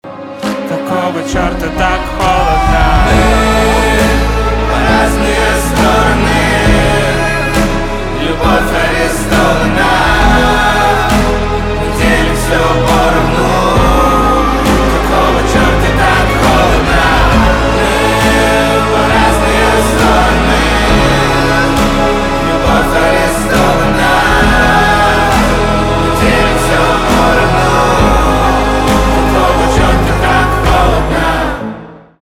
русский рок
грустные , печальные , скрипка , пианино